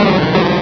Cri de Psykokwak dans Pokémon Rubis et Saphir.